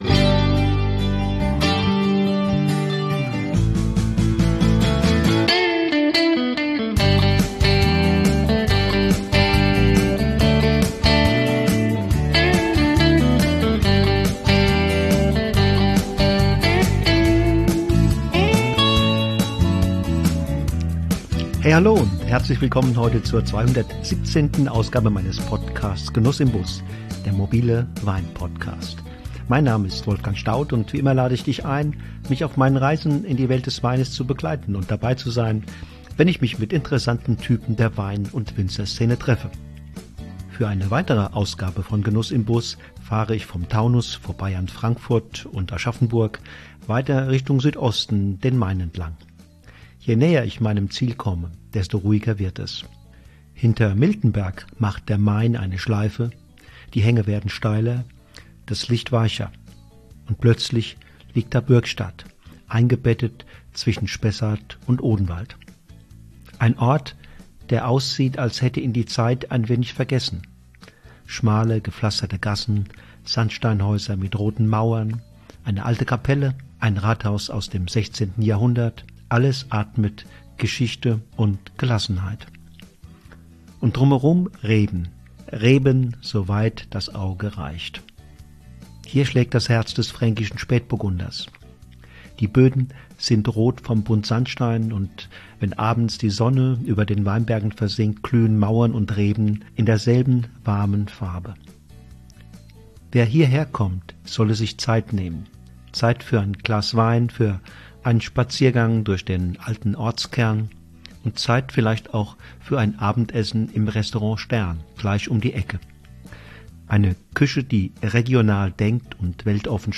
Ein Gespräch über Bio, Freiräume und den Weg in die „Champions League“ des Spätburgunders.